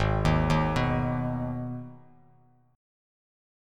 G#m Chord
Listen to G#m strummed